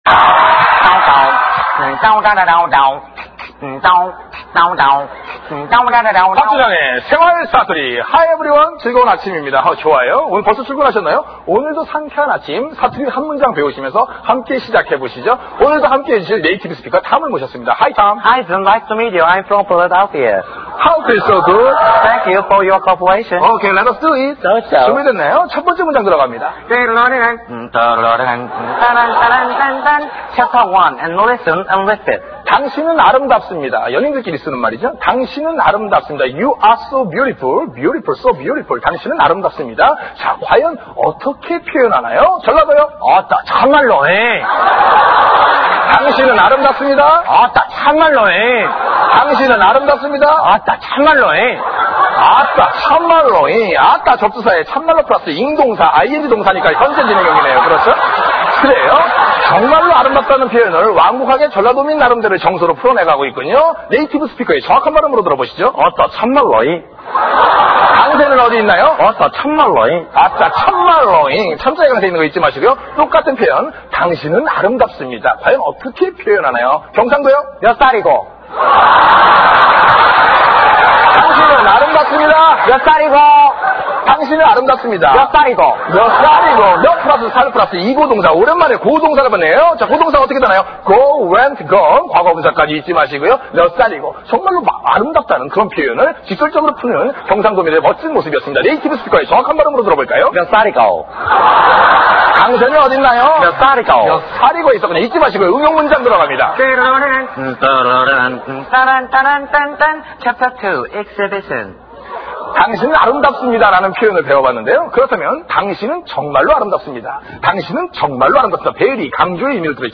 [318] 생활 사투리 2, 3, 4편들... ^^
생활사투리2.mp3